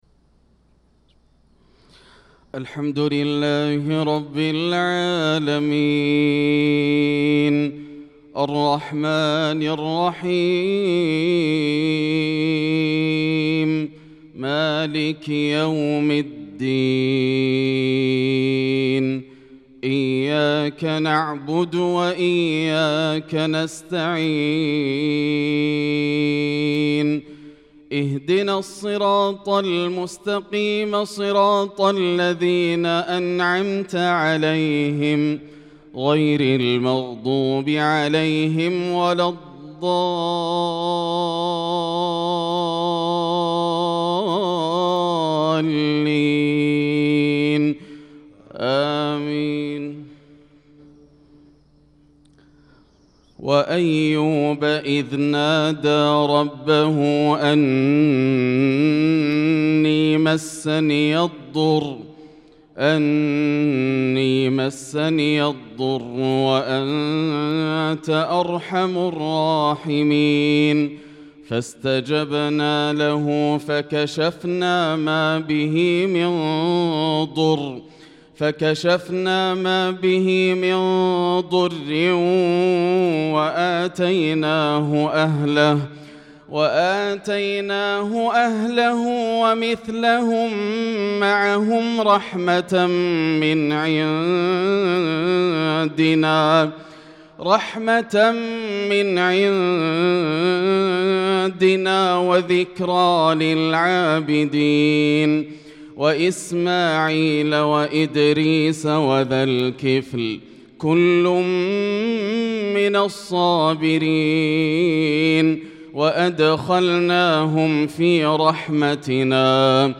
صلاة الفجر للقارئ ياسر الدوسري 7 ذو القعدة 1445 هـ
تِلَاوَات الْحَرَمَيْن .